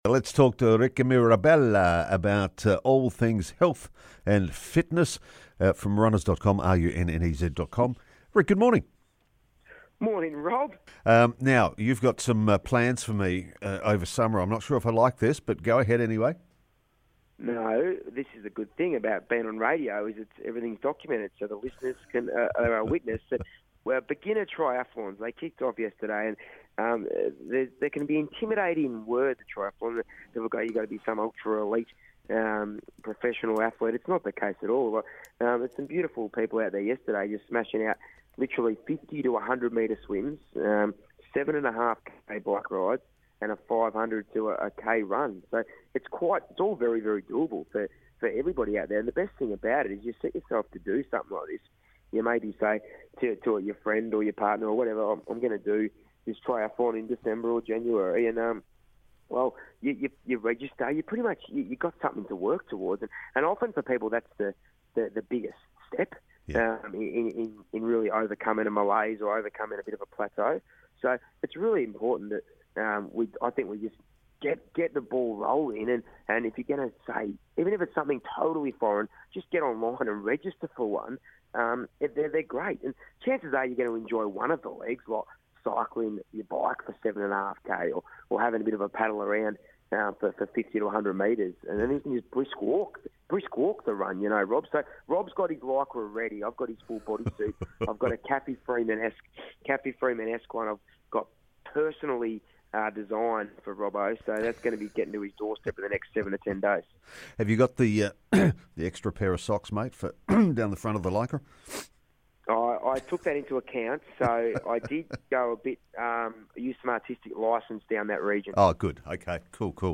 And he says anyone can do it...hear the interview right here...